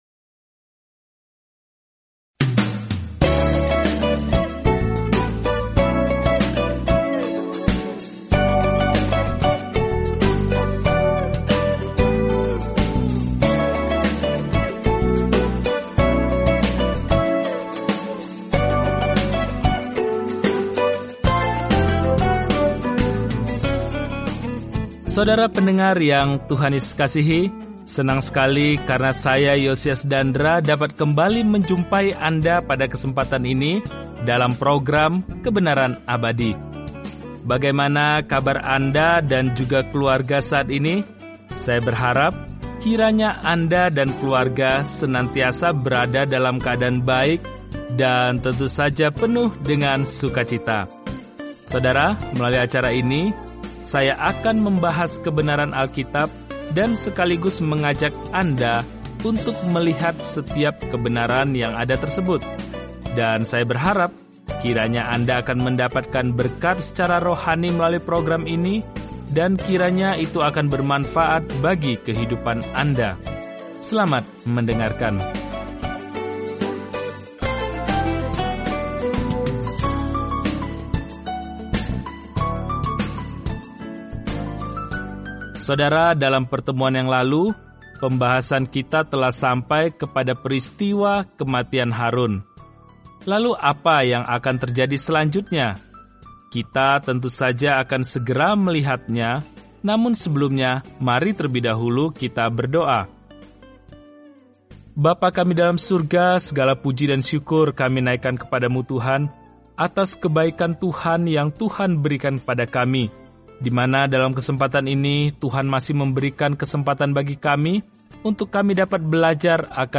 Firman Tuhan, Alkitab Bilangan 21 Bilangan 22:1-5 Hari 13 Mulai Rencana ini Hari 15 Tentang Rencana ini Dalam kitab Bilangan, kita berjalan, mengembara, dan beribadah bersama Israel selama 40 tahun di padang gurun. Jelajahi Numbers setiap hari sambil mendengarkan studi audio dan membaca ayat-ayat tertentu dari firman Tuhan.